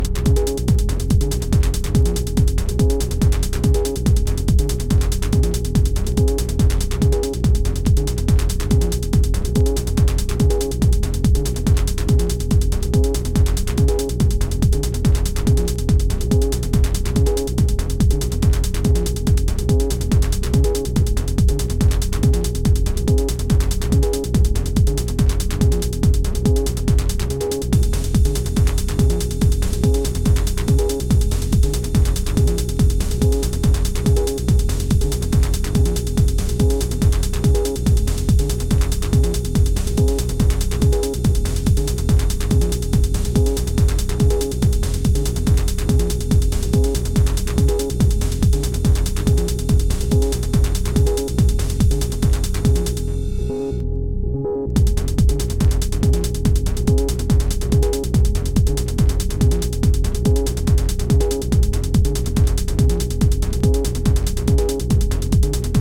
BPM140越のDub Technoトラック